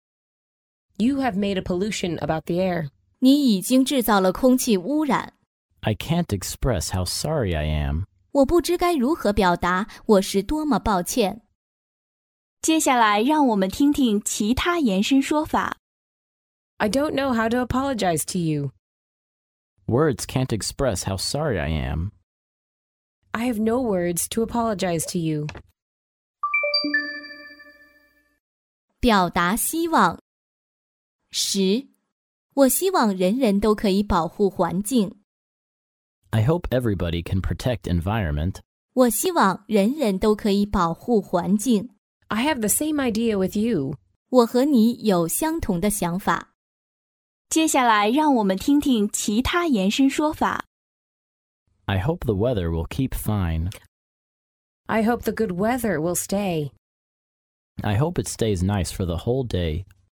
在线英语听力室法律英语就该这么说 第99期:我不知该如何表达我是多么抱歉的听力文件下载,《法律英语就该这么说》栏目收录各种特定情境中的常用法律英语。真人发音的朗读版帮助网友熟读熟记，在工作中举一反三，游刃有余。